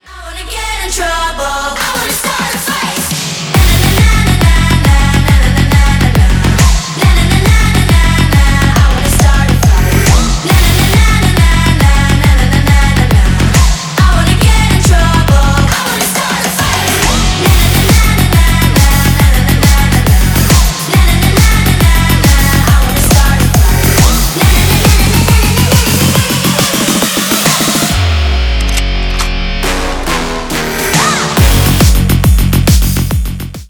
Электроника
клубные